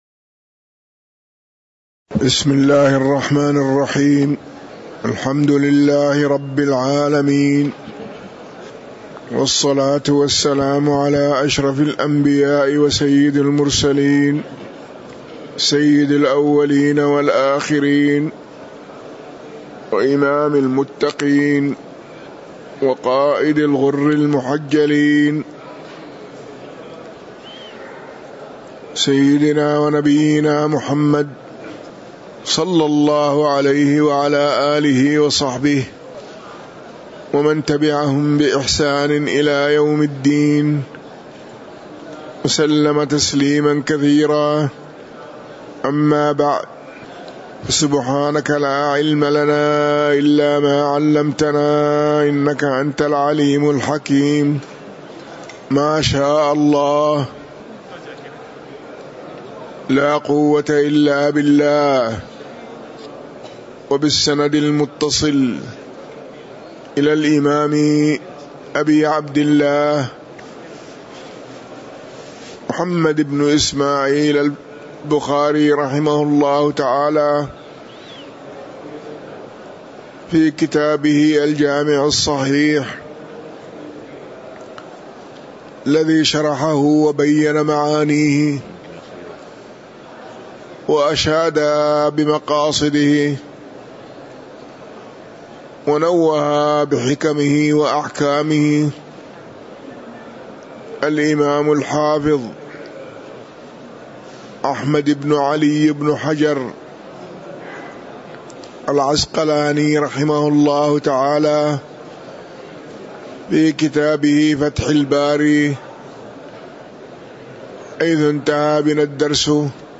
تاريخ النشر ٢٩ شوال ١٤٤٣ هـ المكان: المسجد النبوي الشيخ